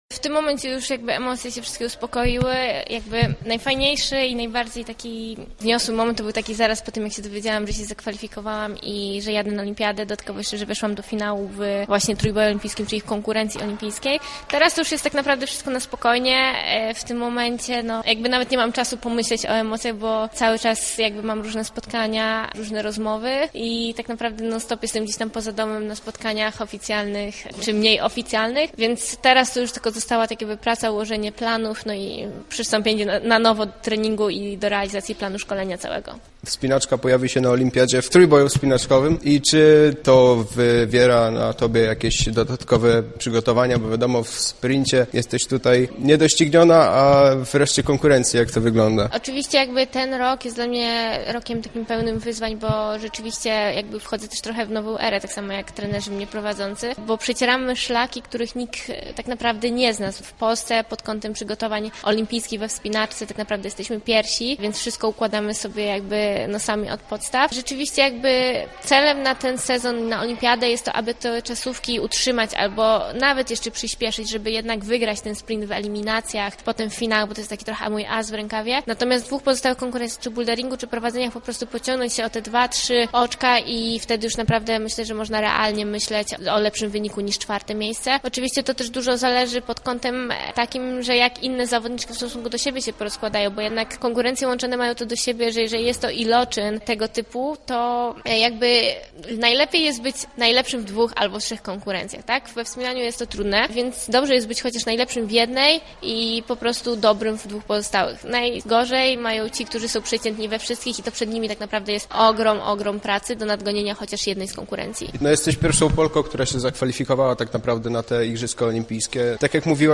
My z Aleksandrą Mirosław porozmawialiśmy między innymi o jej odczuciach po Mistrzostwach Świata i planowanych przygotowaniach do Igrzysk.